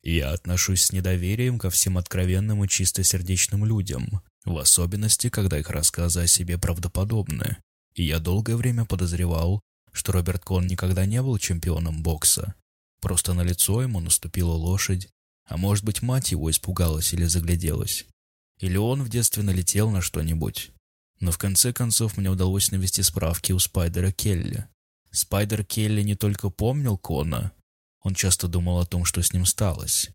Муж, Аудиокнига/Зрелый
Спокойный, глубокий бас-баритон.
Студийное качество записи.